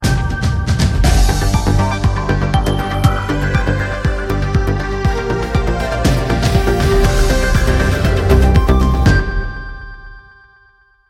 mais donc sans les voix, juste la "musique".